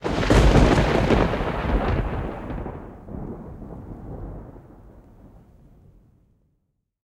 thunder_9.ogg